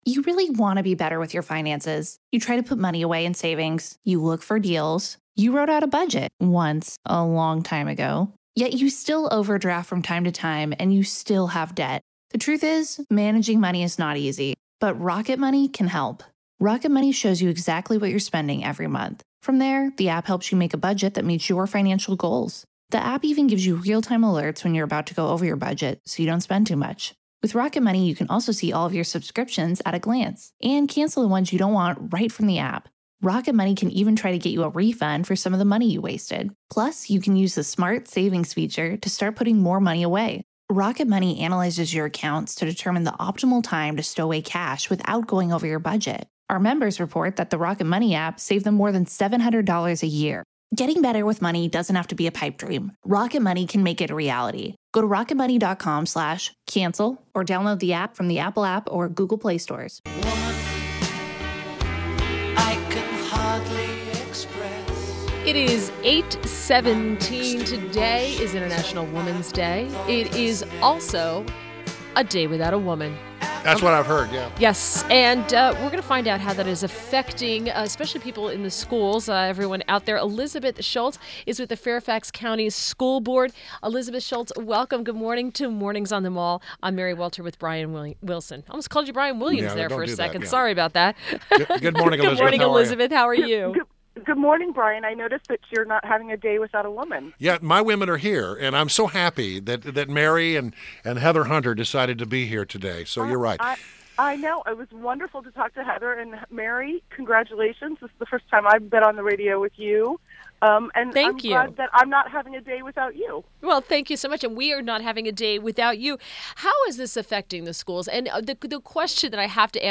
WMAL Interview - ELIZABETH SCHULTZ - 03.08.17